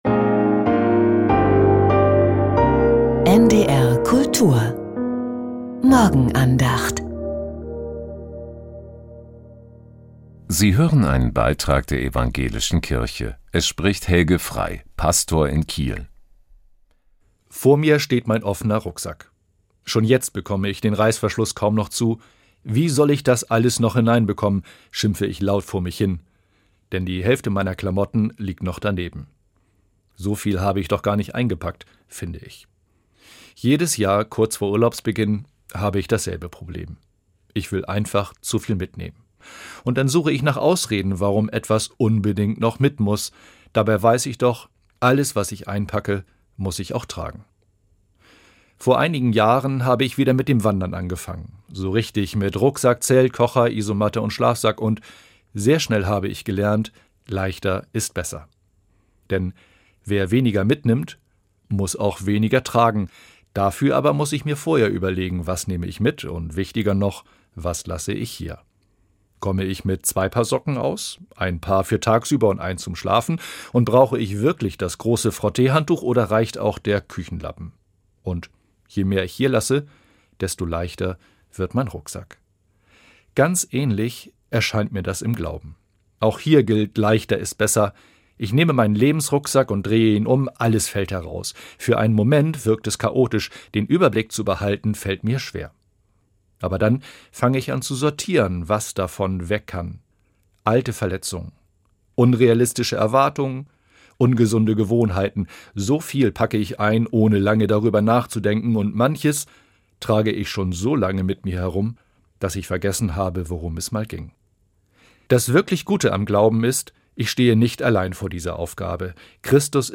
Die Andachten waren auf NDR Info und NDR Kultur zu hören.